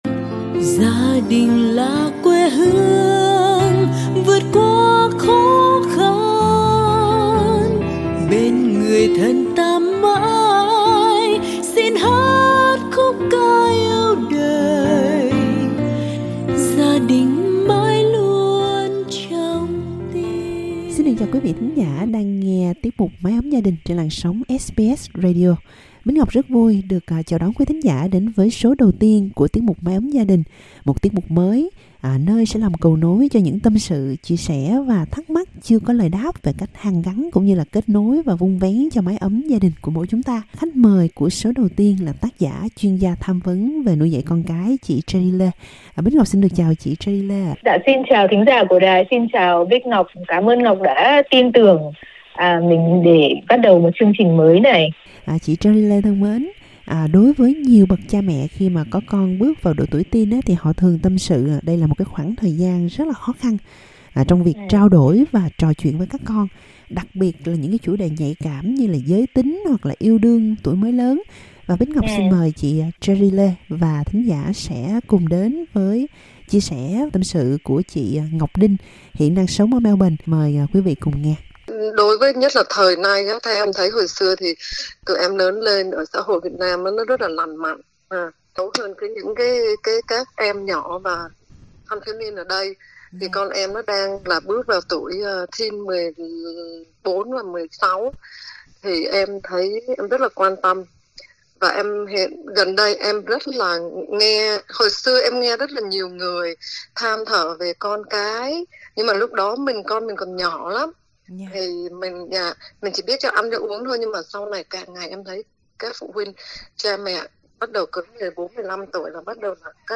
Mái ấm gia đình là một tiết mục mới được phát thanh trên làn sóng của SBS Radio từ tháng 5.
Mỗi tuần, chúng tôi sẽ cùng với các chuyên gia trò chuyện, tìm cách gỡ rối, và chia sẻ với quý thính giả những câu chuyện khó xử, đôi khi không biết bày tỏ cùng ai để giữ gìn hạnh phúc gia đình.